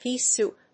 アクセントpéa sòup